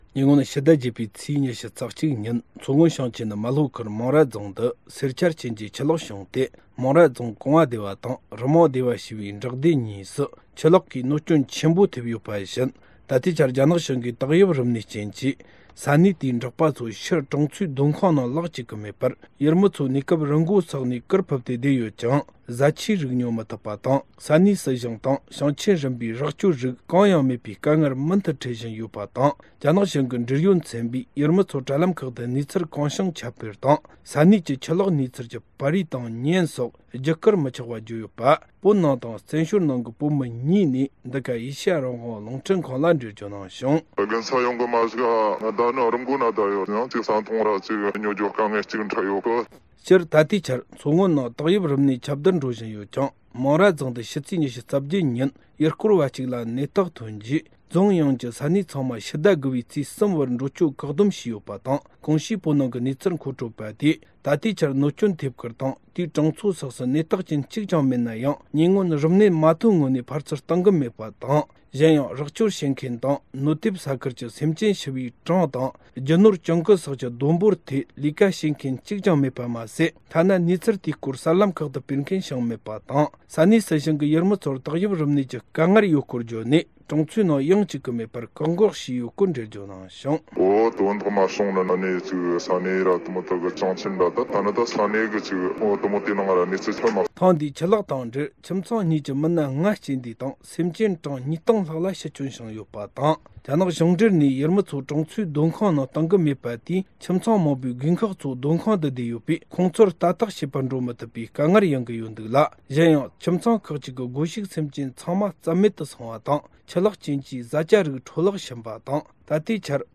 སྒྲ་ལྡན་གསར་འགྱུར། སྒྲ་ཕབ་ལེན།
ཉེ་སྔོན་ཕྱི་ཟླ་༨ཚེས་༢༡ཉིན་མཚོ་སྔོན་ཞིང་ཆེན་མཚོ་ལྷོ་ཁུལ་མང་ར་རྫོང་དུ་སེར་ཆར་རྐྱེན་གྱིས་ཆུ་ལོག་བྱུང་སྟེ། མང་ར་རྫོང་གོང་བ་སྡེ་བ་དང་རུ་མང་སྡེ་བ་ཞེས་པའི་འབྲོག་སྡེ་གཉིས་སུ་ཆུ་ལོག་གནོད་སྐྱོན་ཆེན་པོ་ཐེབས་ཡོད་པ་བཞིན། ད་ལྟའི་ཆར་རྒྱ་ནག་གཞུང་གིས་ཏོག་དབྱིབས་རིམས་ནད་རྐྱེན་གྱིས་ས་གནས་དེའི་འབྲོག་པ་ཚོ་ཕྱིར་གྲོང་ཚོའི་སྡོད་ཁང་ནང་ལོག་བཅུག་གི་མེད་པར། ཡུལ་མི་ཚོ་གནས་སྐབས་རི་མགོ་སོགས་ནས་གུར་ཕུབས་སྟེ་བསྡད་ཡོད་ཀྱང་། བཟའ་ཆས་རིགས་ཉོ་མི་ཐུབ་པ་དང་། ས་གནས་སྲིད་གཞུང་དང་ཞིང་ཆེན་རིམ་པའི་རོགས་སྐྱོར་རིགས་གང་ཡང་མེད་པའི་དཀའ་ངལ་མུ་མཐུད་འཕྲད་བཞིན་ཡོད་པ་དང་། རྒྱ་ནག་གཞུང་གི་འབྲེལ་ཡོད་ཚན་པས་ཡུལ་མི་ཚོར་དྲ་ལམ་ཁག་ཏུ་གནས་ཚུལ་གང་བྱུང་ཁྱབ་སྤེལ་དང་། ས་གནས་ཀྱི་ཆུ་ལོག་གནས་ཚུལ་གྱི་པར་རིས་དང་བརྙན་སོགས་བརྒྱུད་བསྐུར་མི་ཆོག་པ་བརྗོད་ཡོད་པ། བོད་ནང་དང་བཙན་བྱོལ་ནང་གི་བོད་མི་གཉིས་ནས་འགྲེལ་བརྗོད་གནང་བྱུང་།